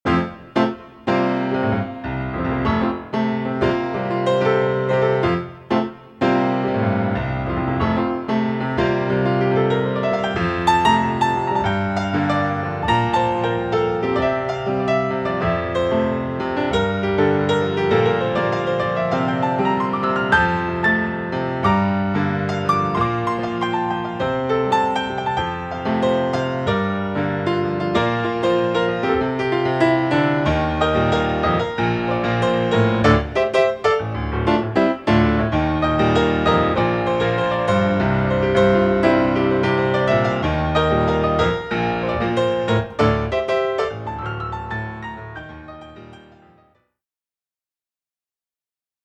ヒーリングＣＤ